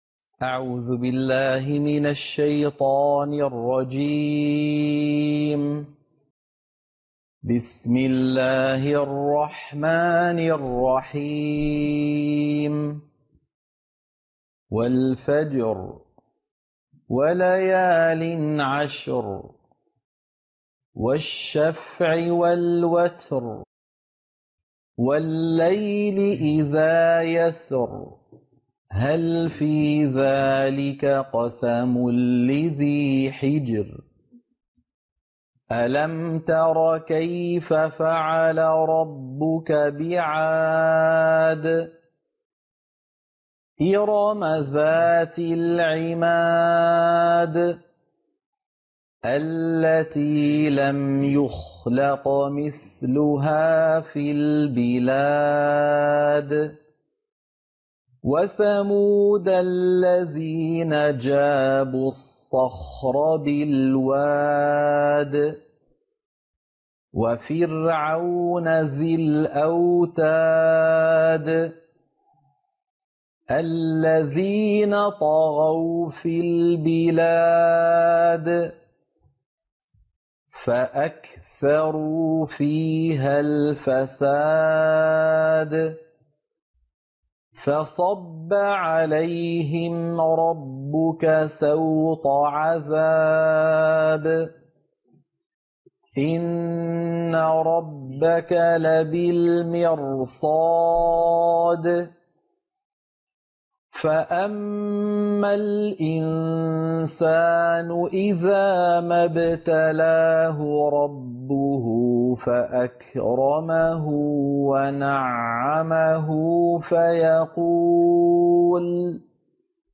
عنوان المادة سورة الفجر - القراءة المنهجية